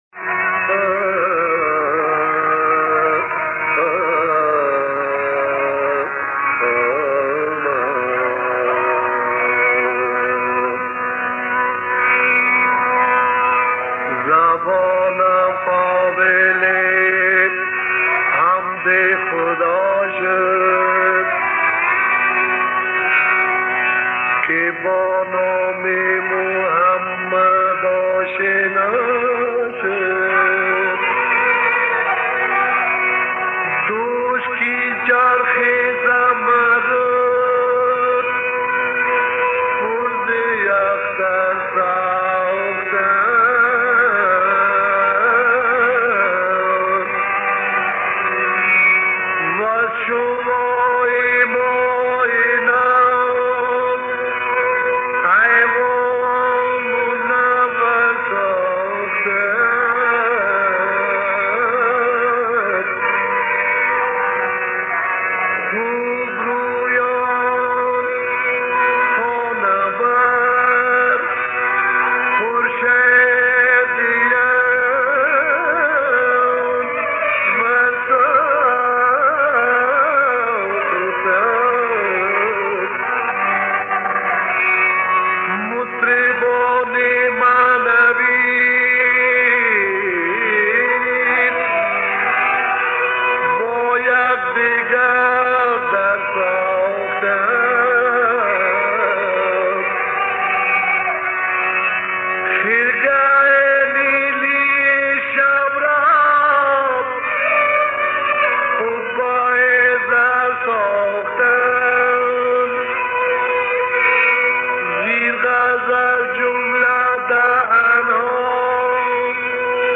این آهنگ، نعتی است برای حضرت پیامبر(ص). شاعر این مسمط را نشناختم و شعر آن را هم بسیار با زحمت پیاده کردم به خاطر کیفیت پایین صدا.